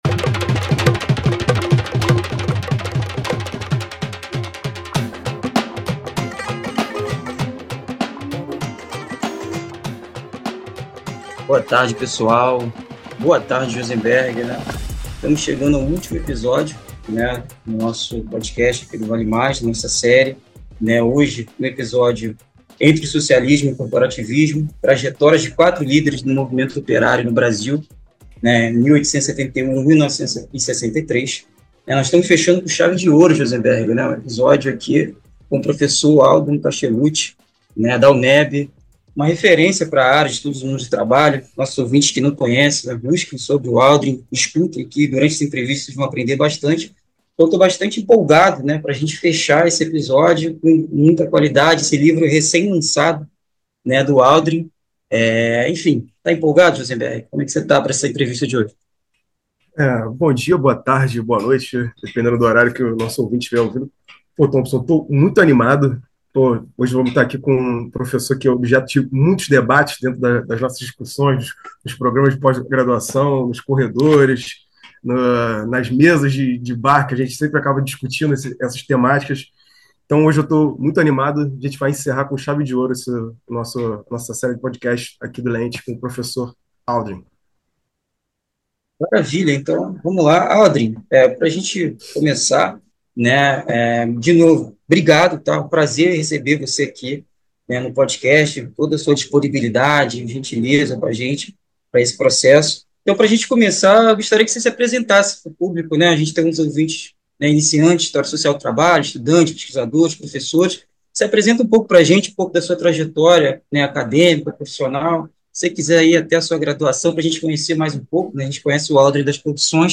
Nessa temporada, convidamos pesquisadores para discutir livros e teses recentes que aprofundam debates interdisciplinares sobre os mundos do trabalho.